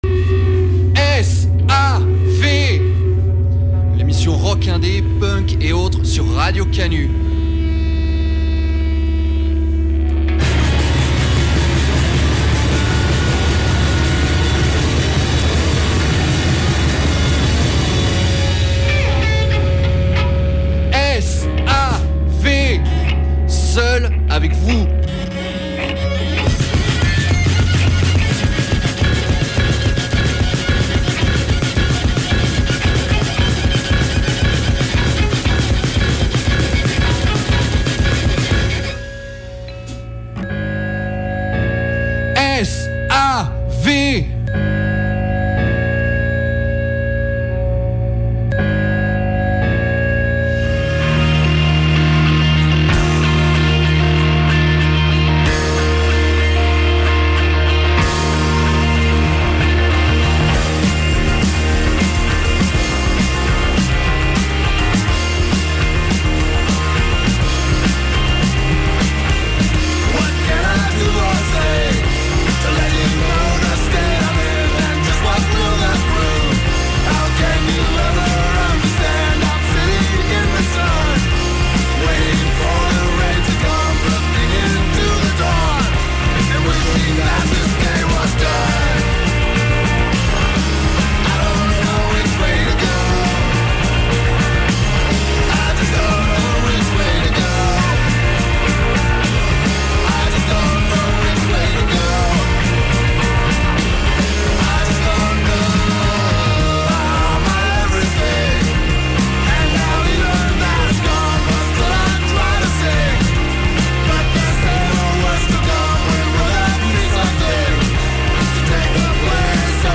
Mix indie/punk/hardcore et autres pour commencer le mois !